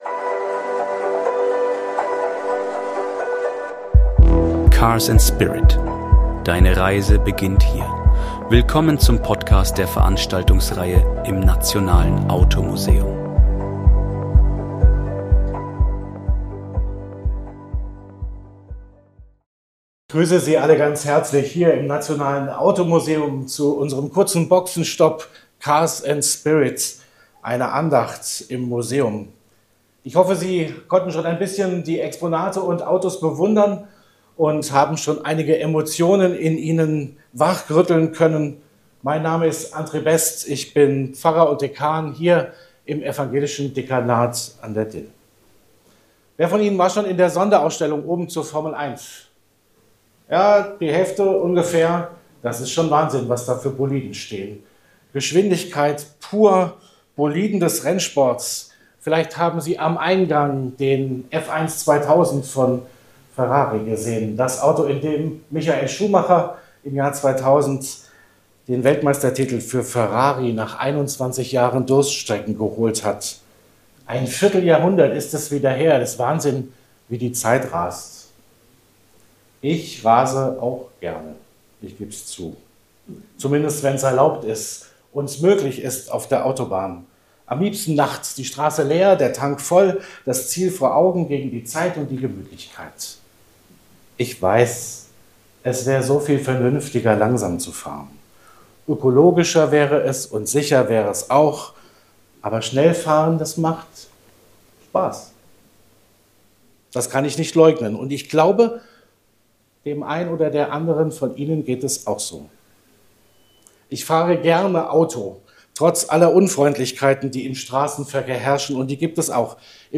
Eine bewegende Andacht zwischen Mythos, PS und persönlicher Haltung.